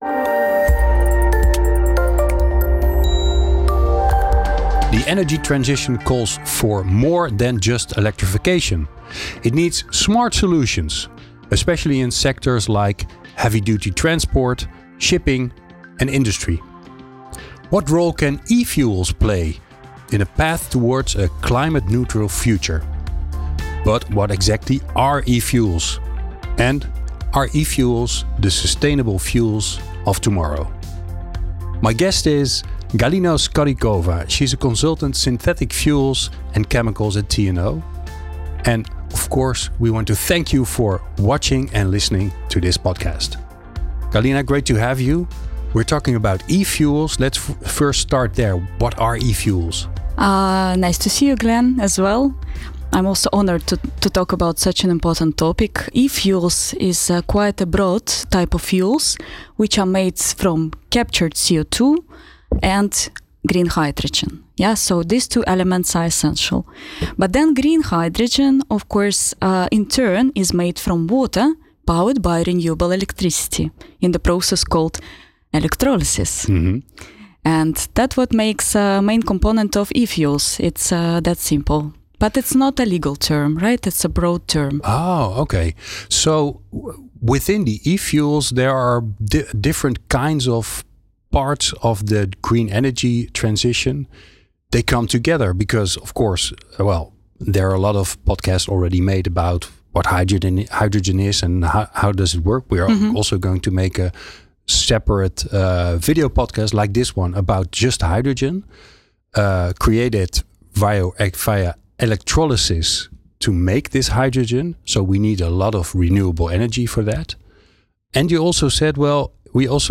Luister naar het gesprek of bekijk de aflevering als vodcast voor extra verdieping en visuele context.